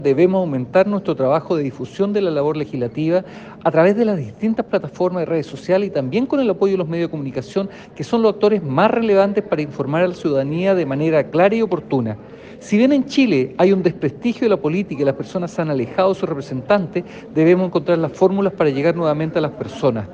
Con mucha más autocrítica respondió el diputado del Partido Socialista, Marcos Ilabaca, afirmando que se trata de resultados preocupantes.
diputado-barometro-los-rios.mp3